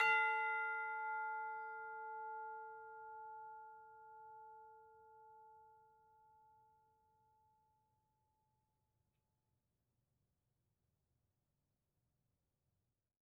Percussion
TB_hit_F5_v3_rr1.wav